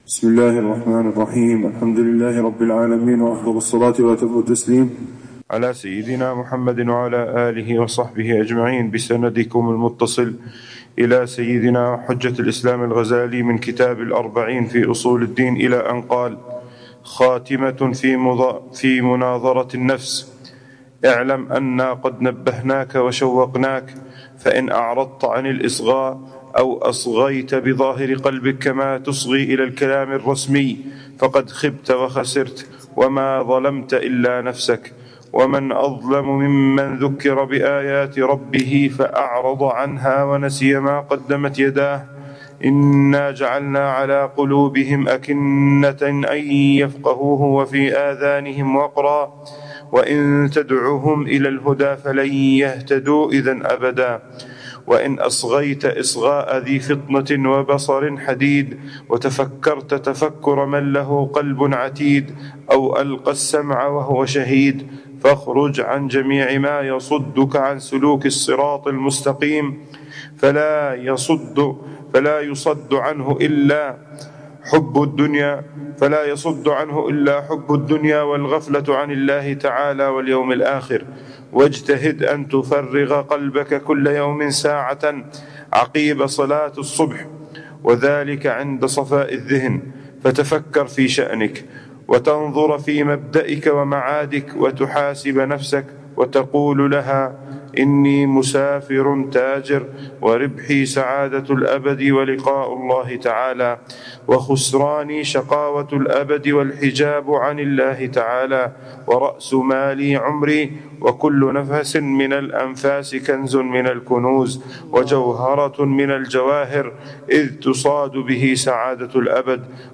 الدرس ال49 في شرح الأربعين في أصول الدين: مناظرة النفس: كيف تخاطب نفسك كل صباح لتسلك الصراط المستقيم، وتخرج من الغفلة إلى نور الحضور